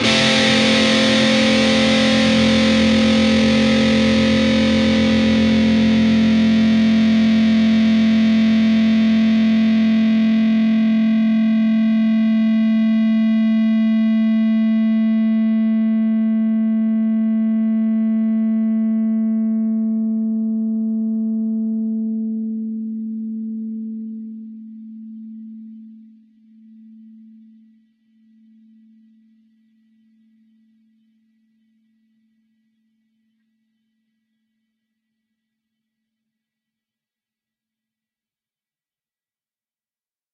失真吉他和弦 " Dist Chr D G strs 12th up
描述：在D（4）弦和G（3）弦上都有12音阶的指法。上弦。
标签： 和弦 扭曲 扭曲的吉他 失真 吉他 吉他弦 铅 - 吉他
声道立体声